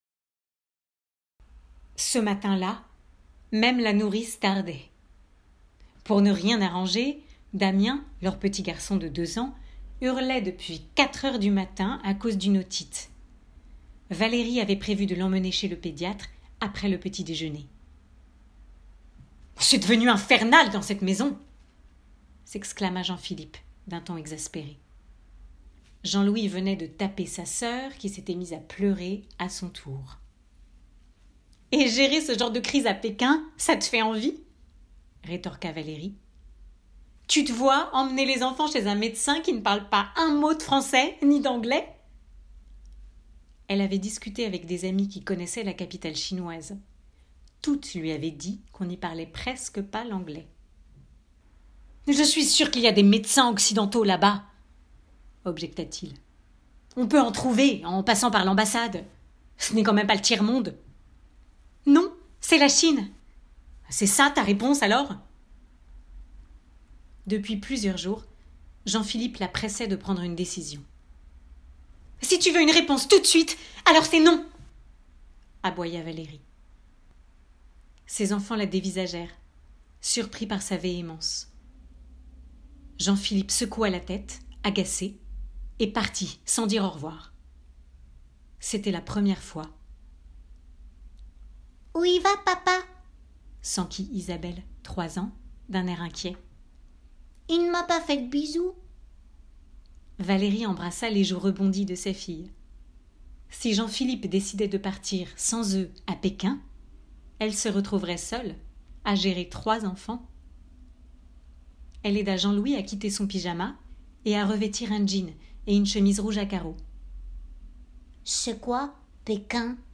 Voix off
Livre - dialogues